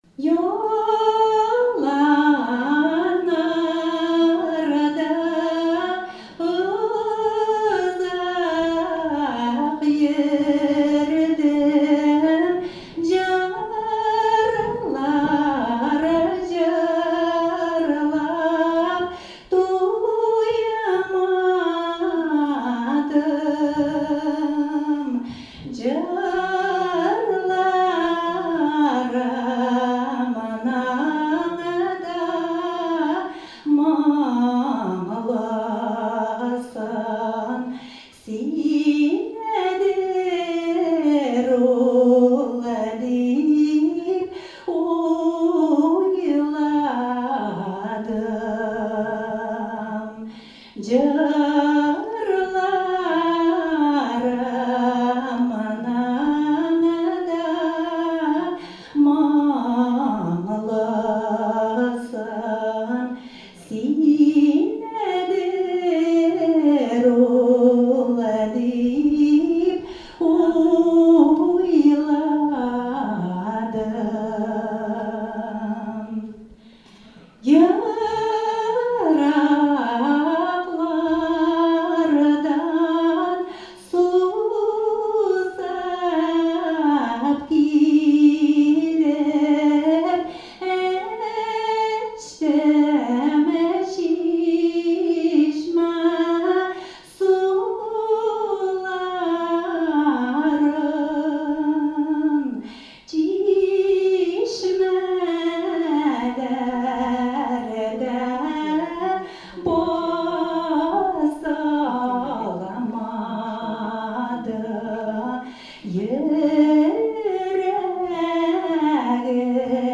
"Себер йолдызлары" фестивале быел җиденче мәртәбә оештырылды.